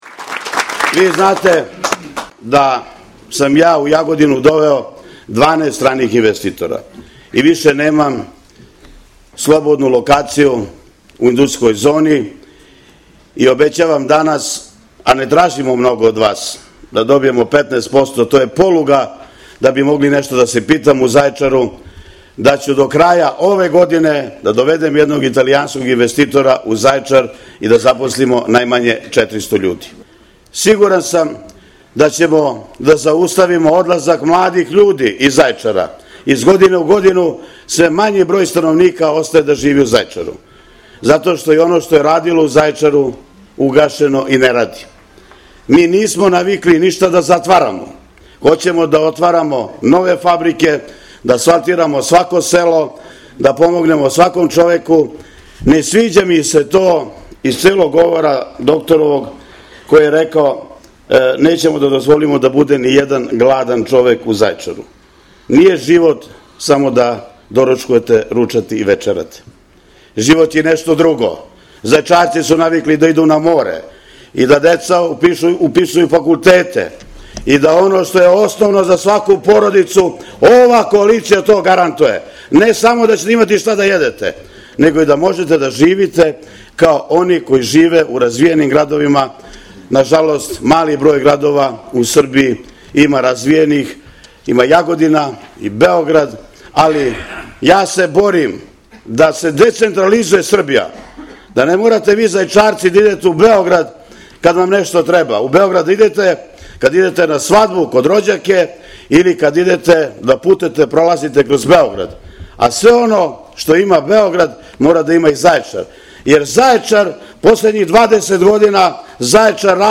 Pred velikim brojem Zaječaraca, članova i simpatizera Socijalističke partije Srbije i Jedinstvene Srbije danas je u Zaječaru održana završna konvencija kandidata za odbornike na listi “Ivica Dačić- Socijalistička partija Srbije (SPS), Jedinstvena Srbija (JS).
Dragan Marković Palma je tom prilikom govorio o preduslovima i mogućnostima za dovođenje stranih investitora u Zaječar.